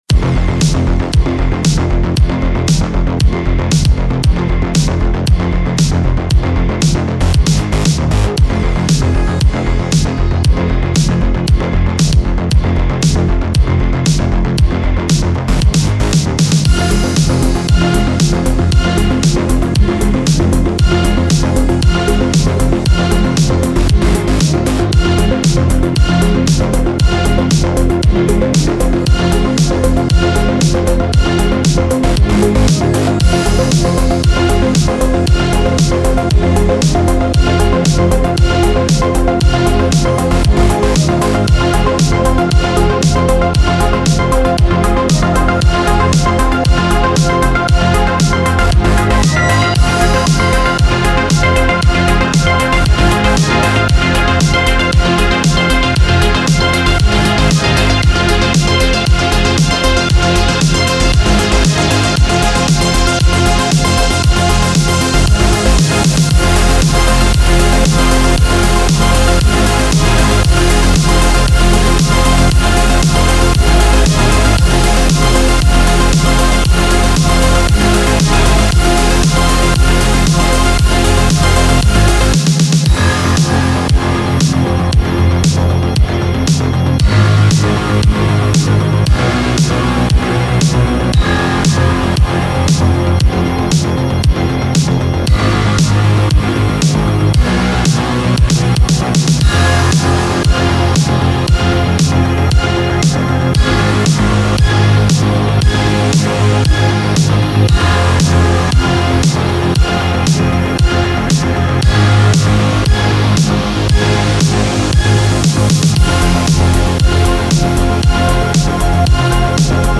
Genre: Synthwave.